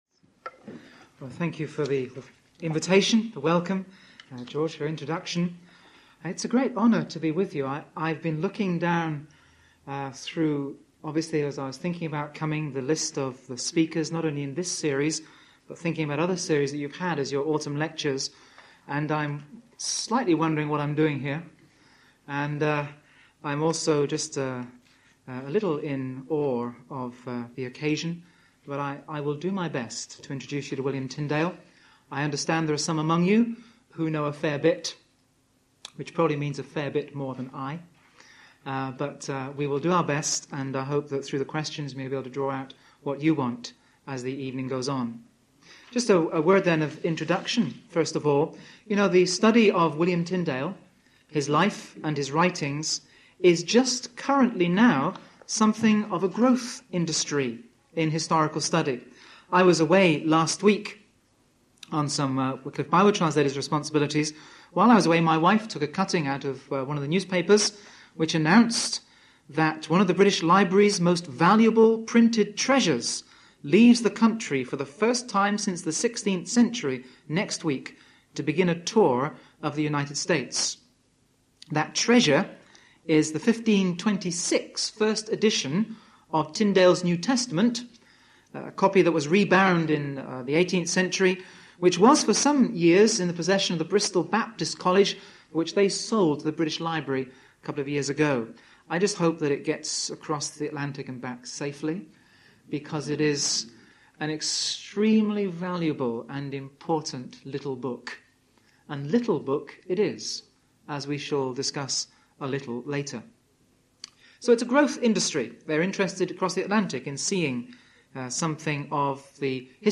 1996 Autumn Lectures